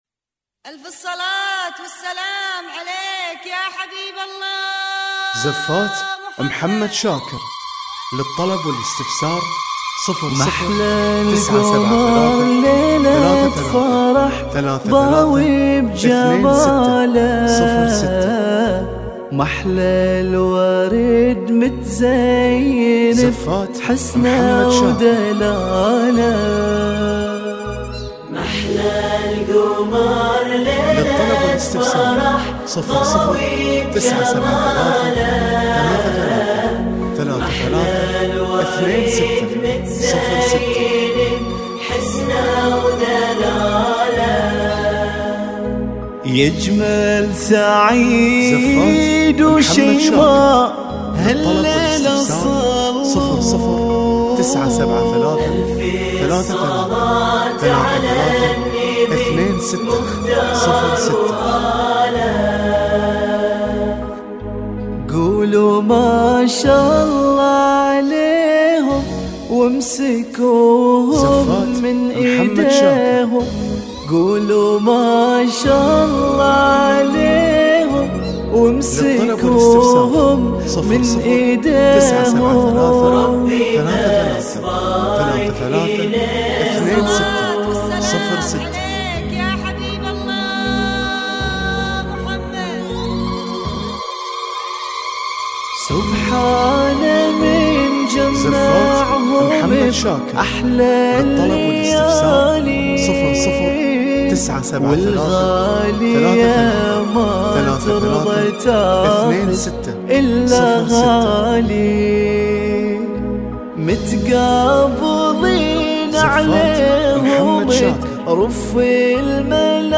جميع الزفات تنفذ بالأسماء
وتتوفر بالموسيقى وبدون موسيقى